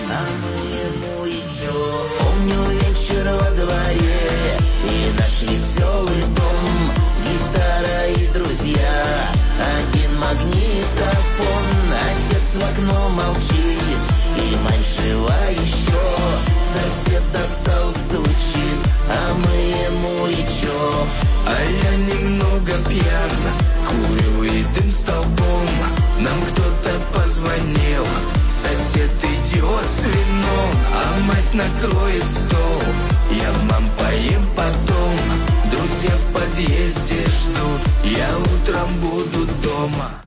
Качество: 320 kbps, stereo
Шансон